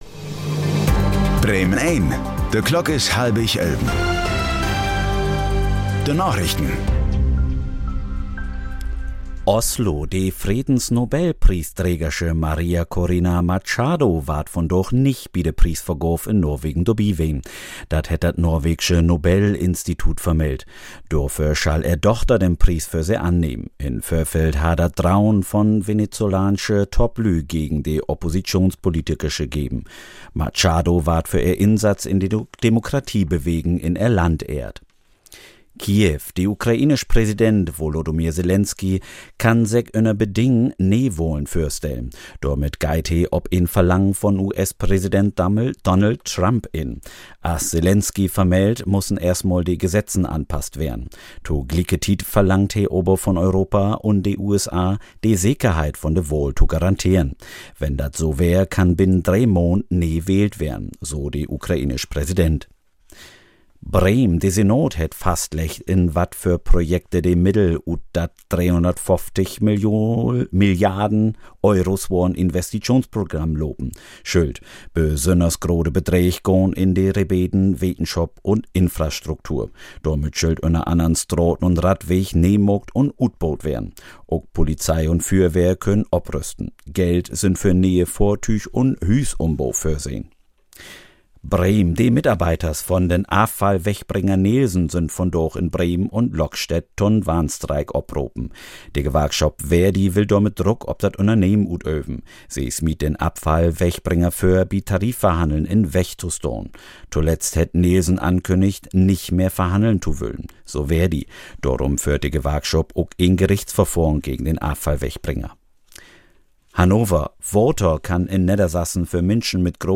Plattdüütsche Narichten vun'n 10. Dezember 2025